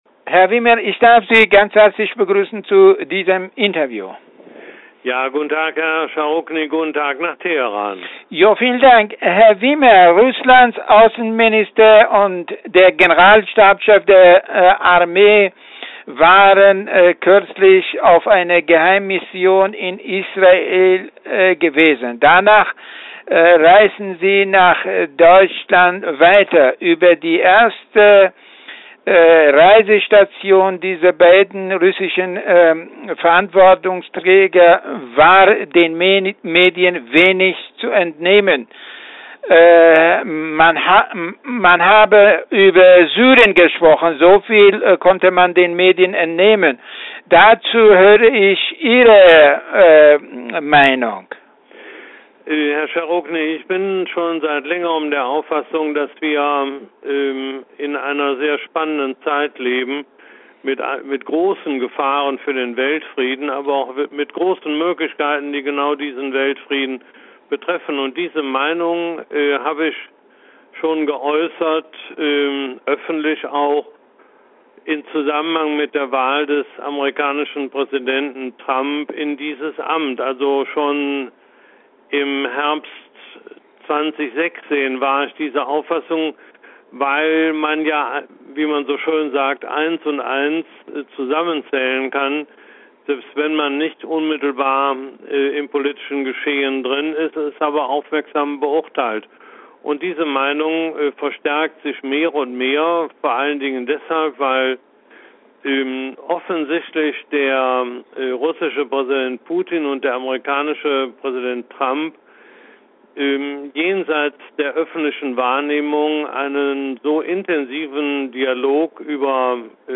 Interview mit Willy Wimmer